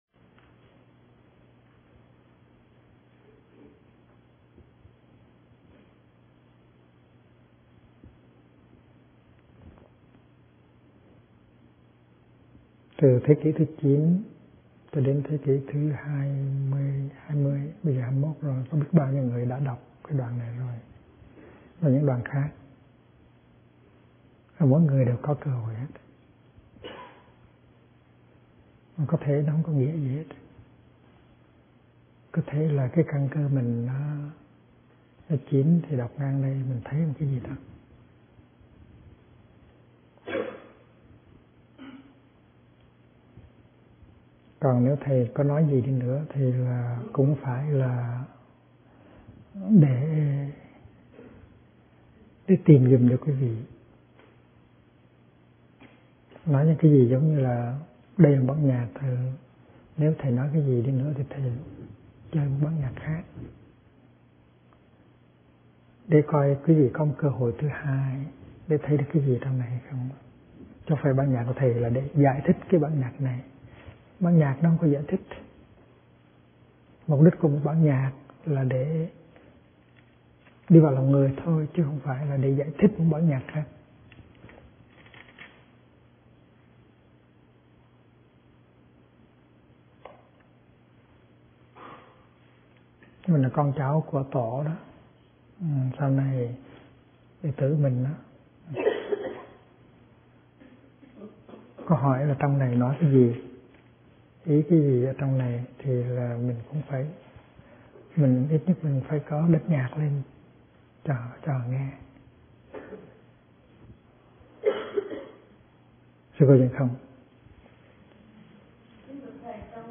Kinh Giảng Đối Trị Cần Có - Thích Nhất Hạnh